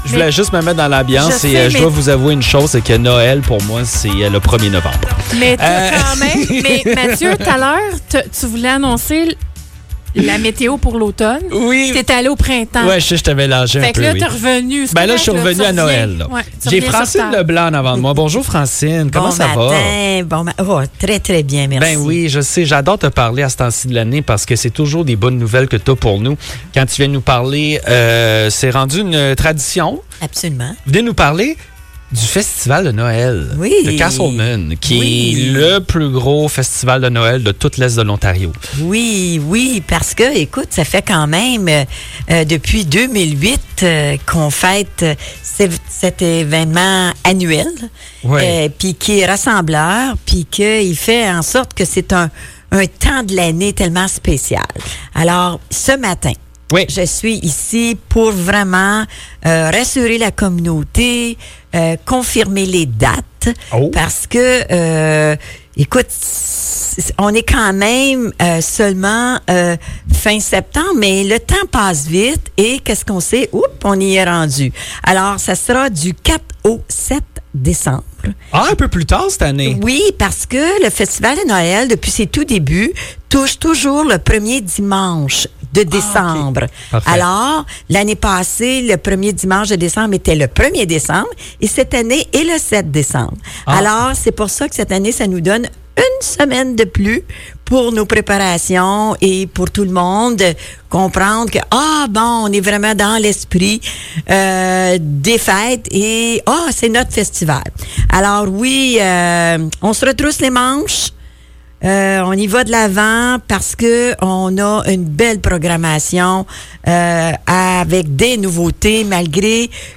Francine Leblanc, conseillère municipale à Casselman, nous présente la programmation de l'édition 2025 du Festival de Noël de Casselman.